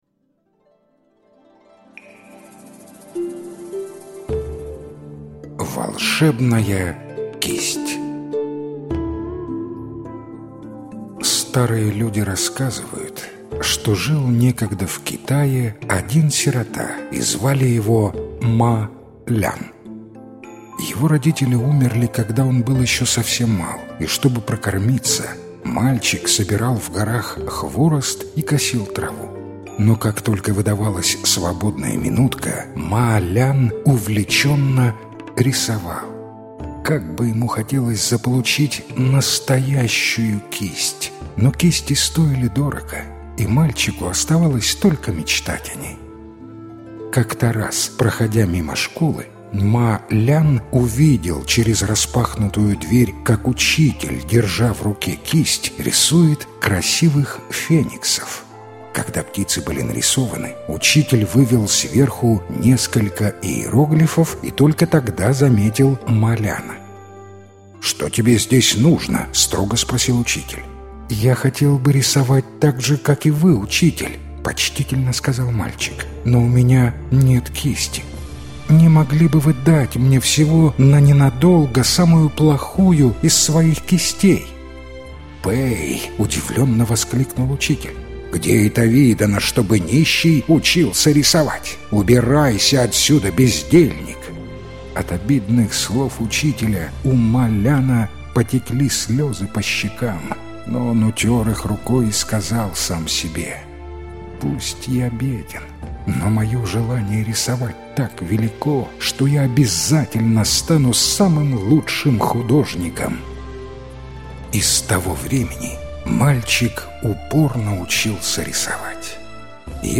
Волшебная кисть Ма Ляна - китайская аудиосказка - слушать